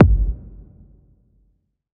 Kick Murdah 2.wav